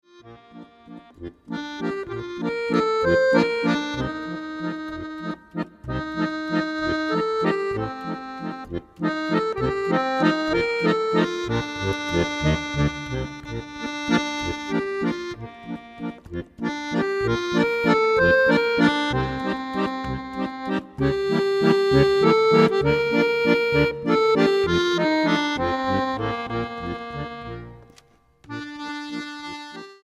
acordeón clásico